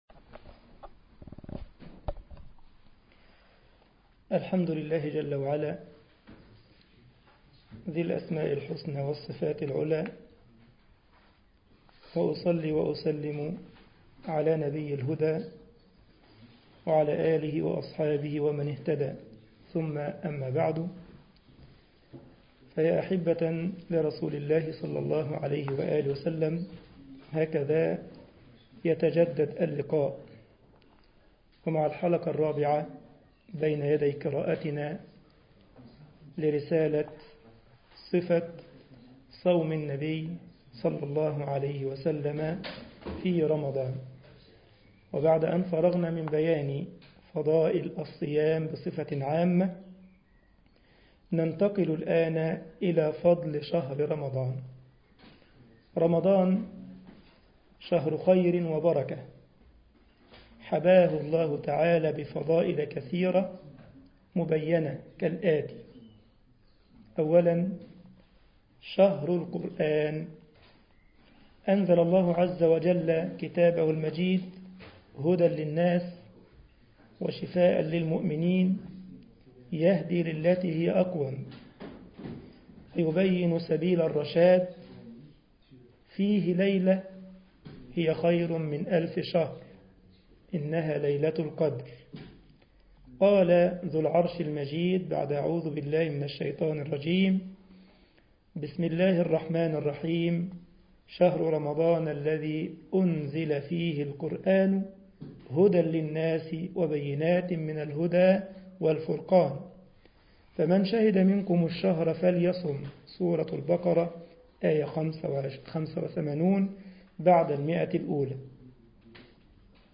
مسجد الجمعية الإسلامية بالسارلند ـ ألمانيا